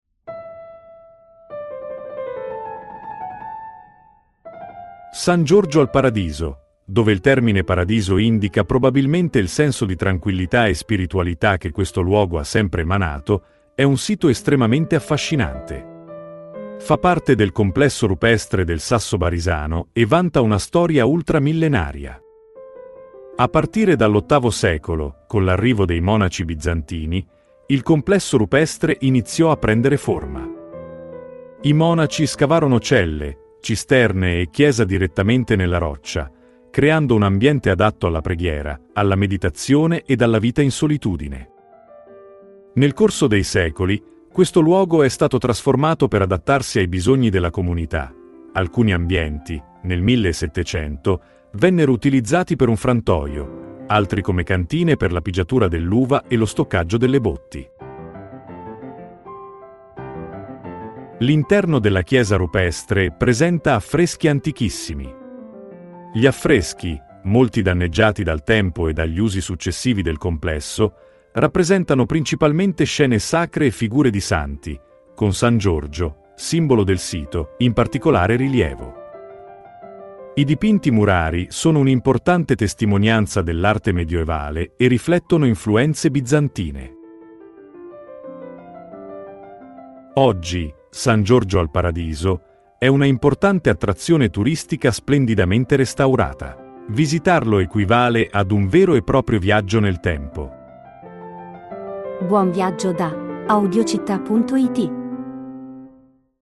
Audioguida Matera – San Giorgio al Paradiso